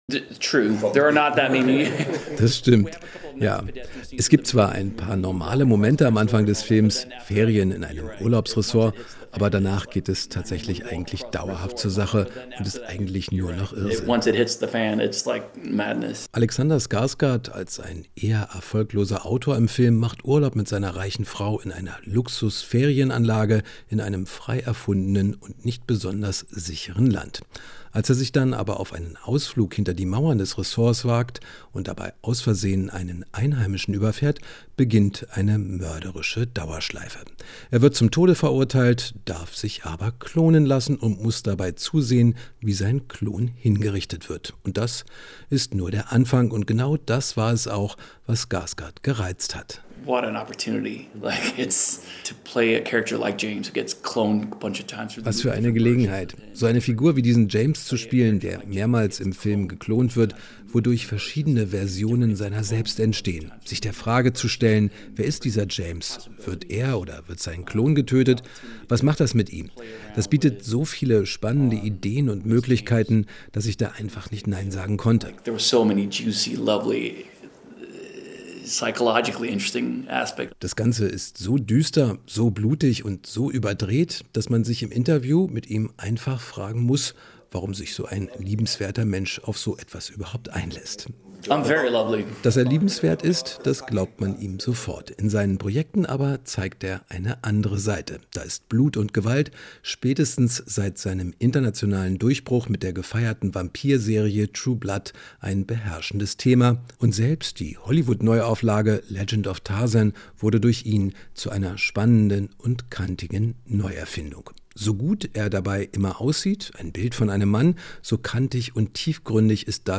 Inforadio Nachrichten, 03.06.2023, 15:00 Uhr - 03.06.2023